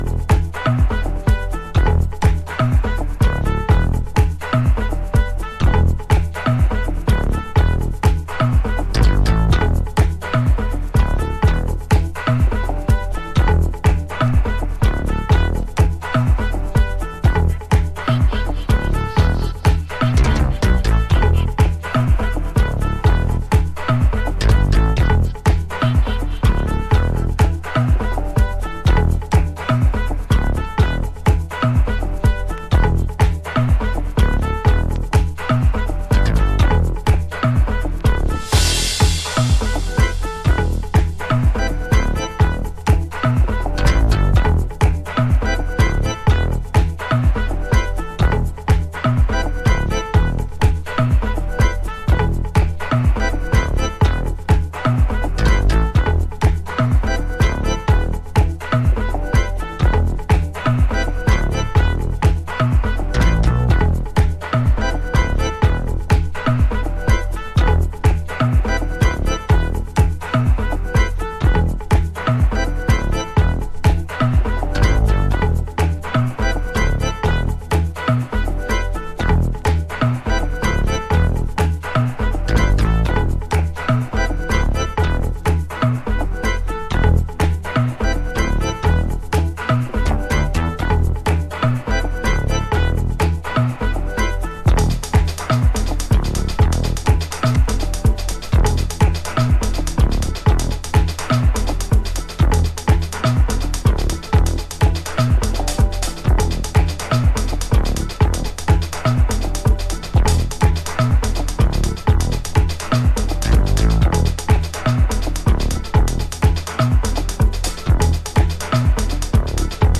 タメの効いたグルーヴでミニマルに展開していきます。使っているベースやシンセが90's 心地。